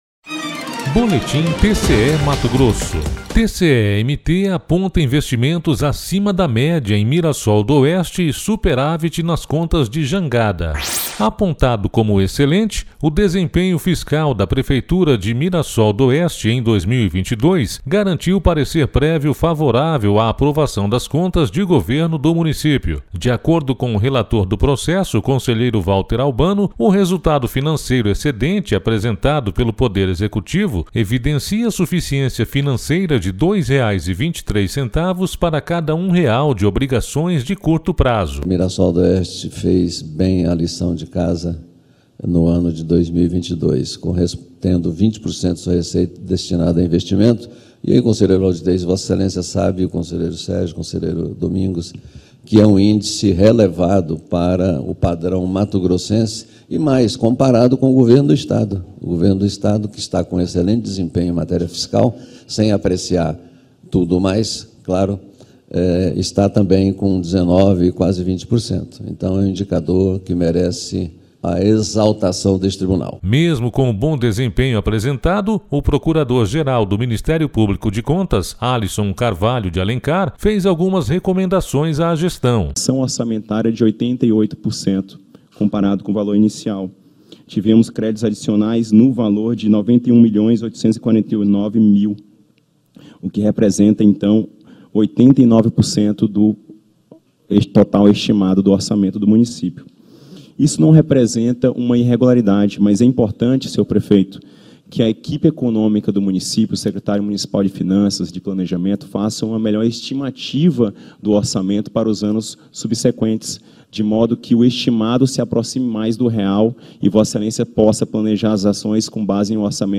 Sonora: Valter Albano – conselheiro do TCE-MT
Sonora: Alisson Carvalho de Alencar - procurador-geral do MPC-MT
Sonora: Sérgio Ricardo – conselheiro do TCE-MT